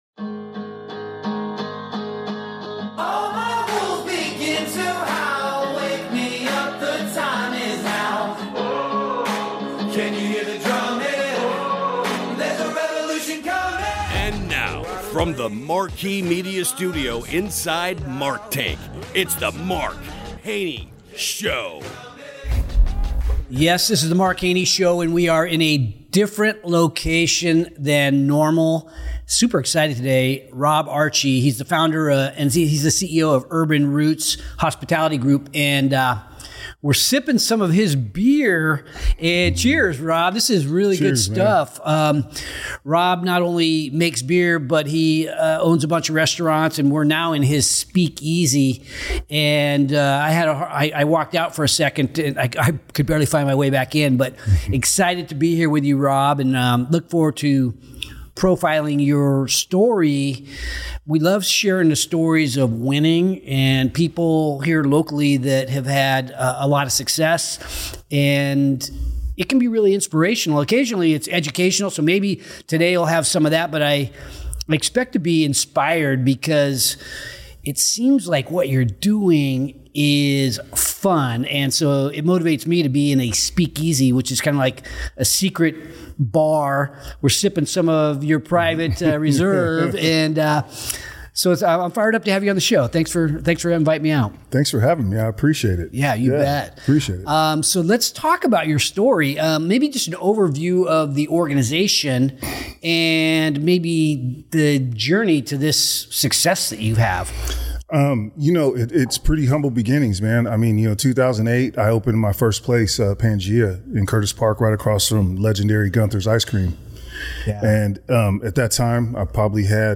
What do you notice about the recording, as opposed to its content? recorded inside his hidden whiskey bar